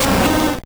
Cri de Cochignon dans Pokémon Or et Argent.